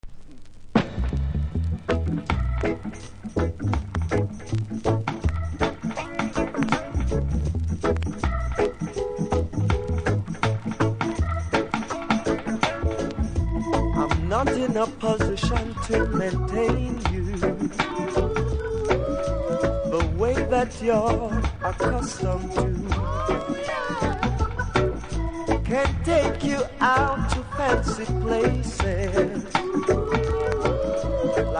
深いキズ数本あり大きめのノイズ拾います。